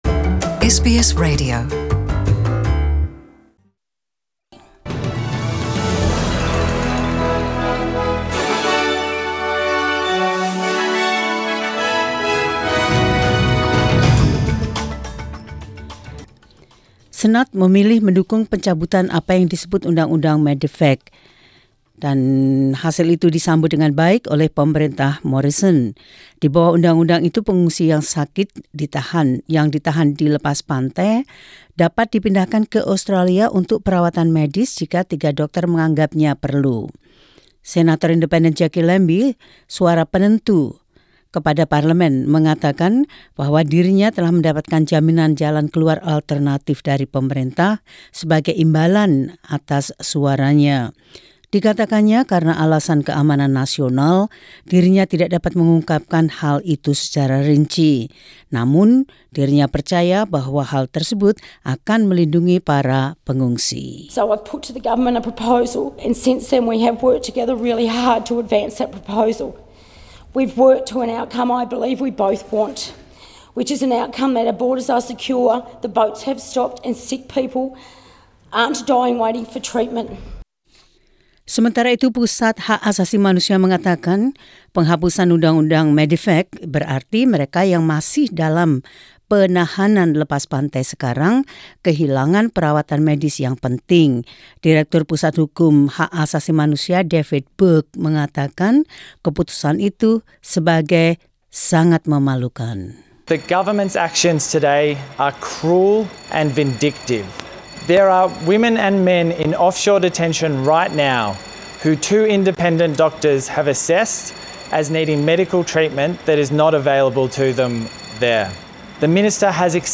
SBS Radio News in Indonesian 4 Dec 2019.